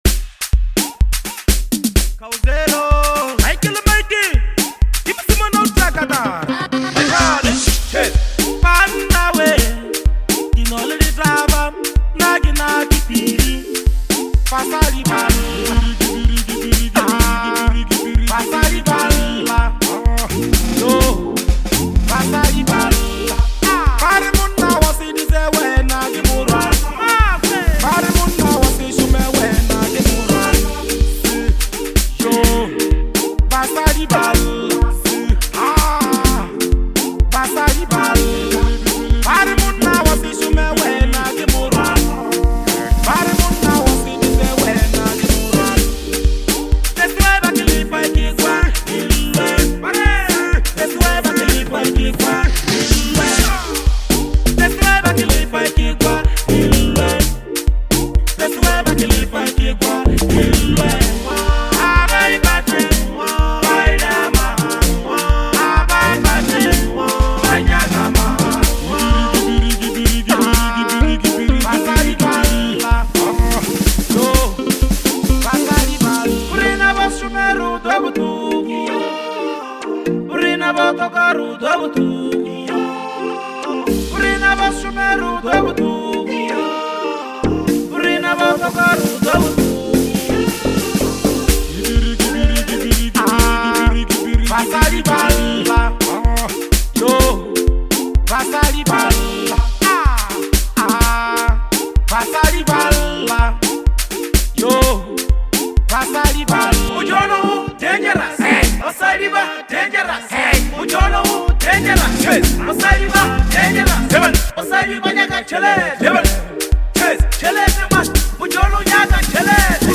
is a deeply emotional song